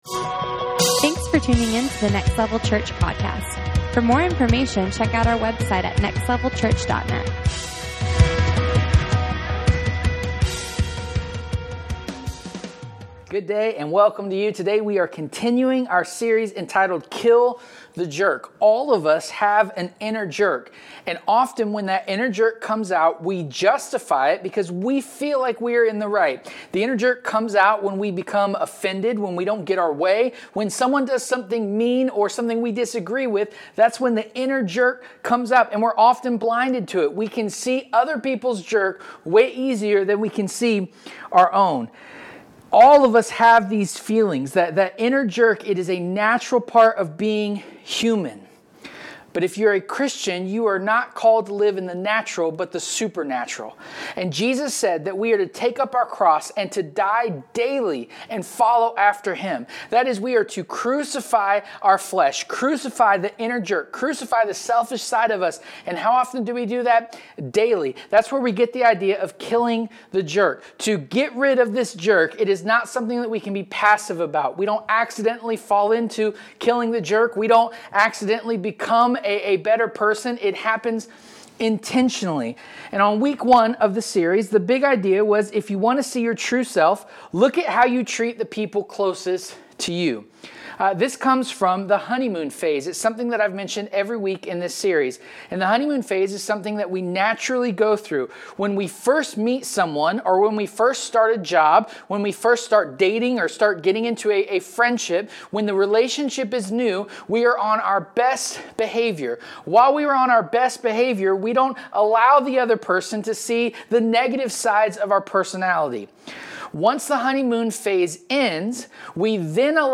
Kill The Jerk Service Type: Sunday Morning Watch We all know a jerk.